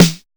626 SNARE 1.wav